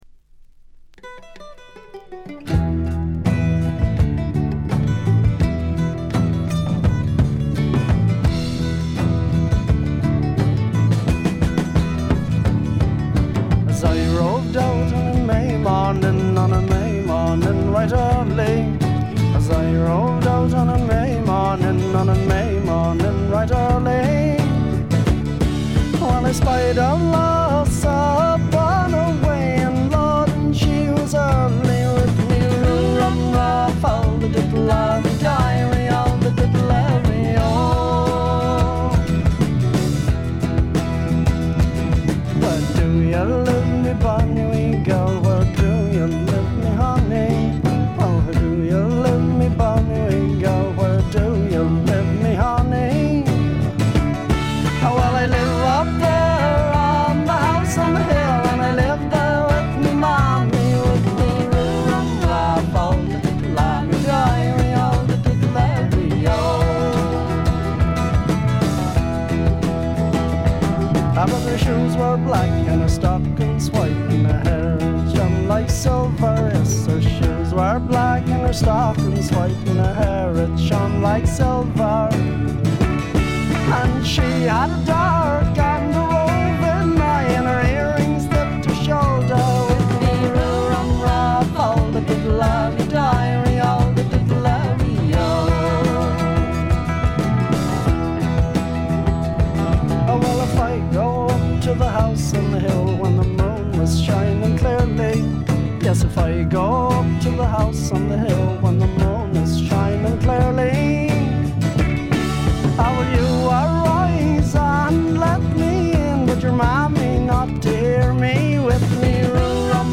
わずかなチリプチ程度。
試聴曲は現品からの取り込み音源です。
Recorded September 1971, Morgan Studio 2.